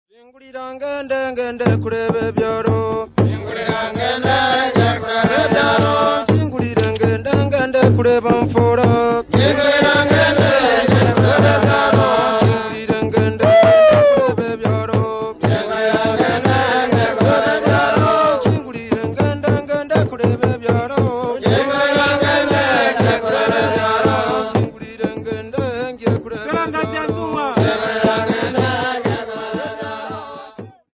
Folk music
Field recordings
Africa Tanzania / Uganda Bukoba f-tz / f-ug
sound recording-musical
They used a drum, they said, to help their singing as normally they would beat the gunwale of their canoe with the paddles. Fishing song, with conical laced drum beaten with sticks and clapping.